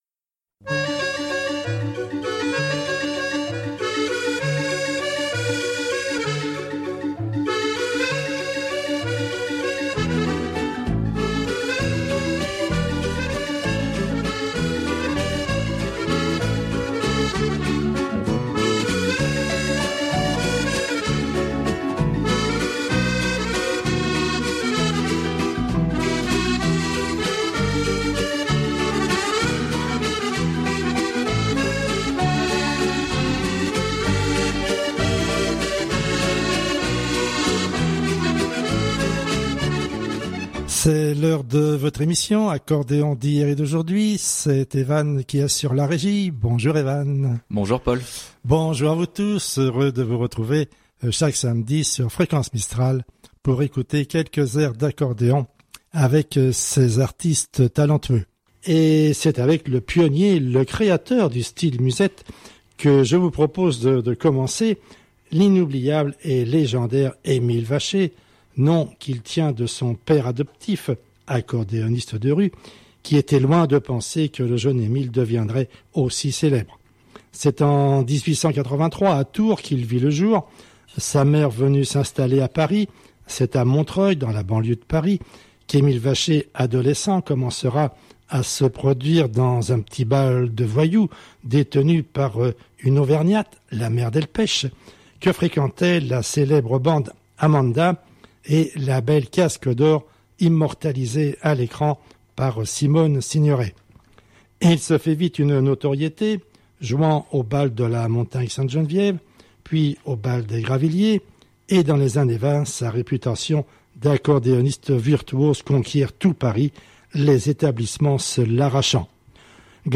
Amateurs d’accordéon bonjour